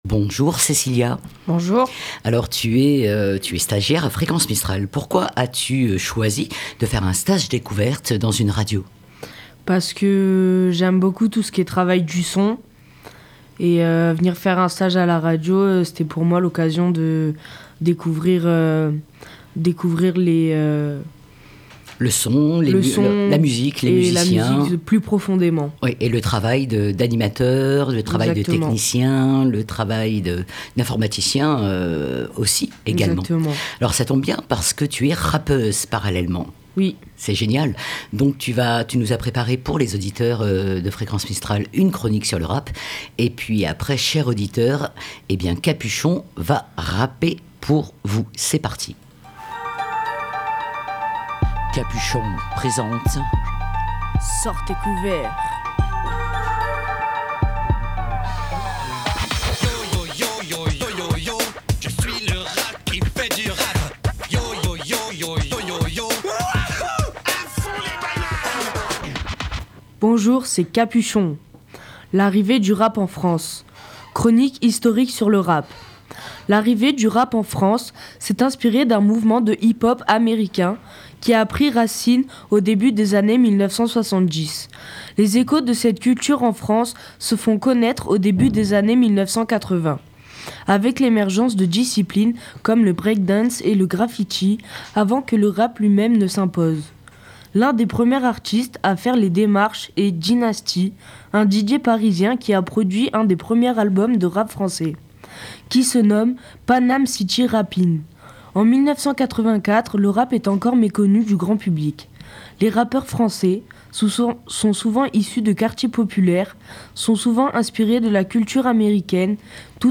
Cette chronique se termine par RAP original que nous vous invitons à découvrir !